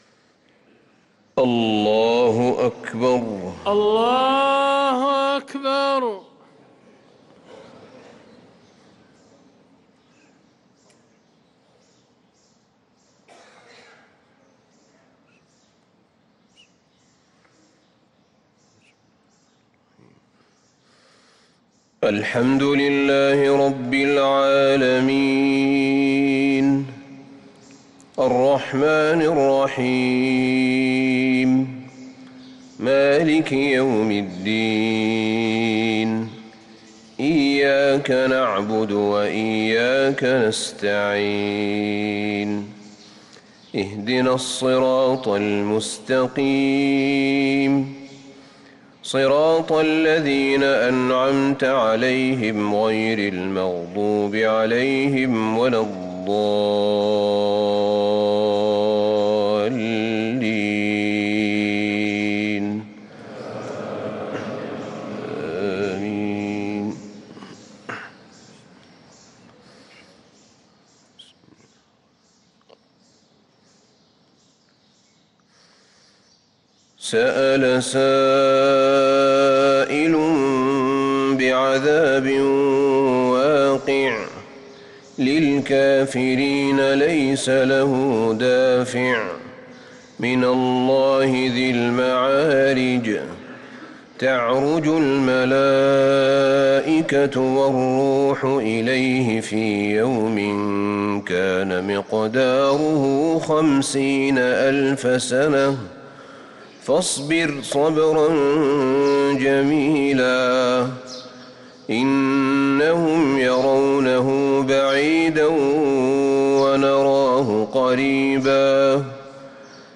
صلاة الفجر للقارئ أحمد بن طالب حميد 5 شعبان 1445 هـ
تِلَاوَات الْحَرَمَيْن .